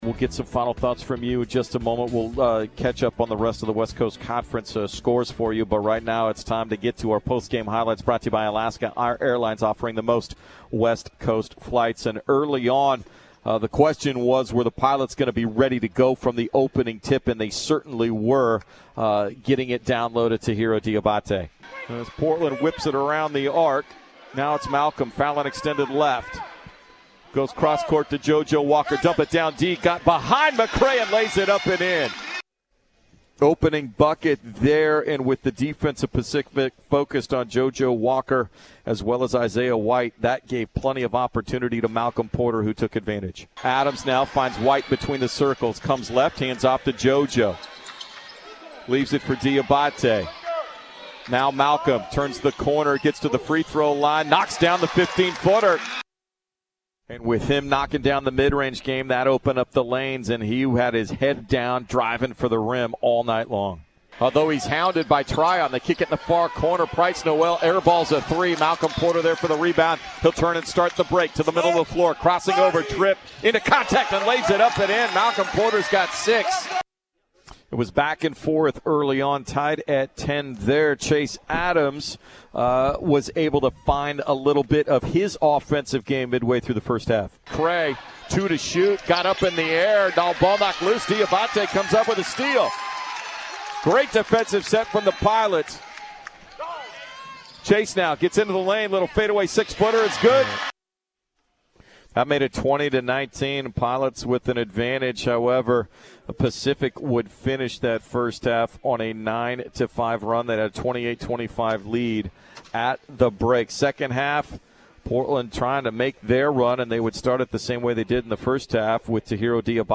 January 16, 2020 Highlights from Portland's 65-55 loss to Pacific at the Chiles Center.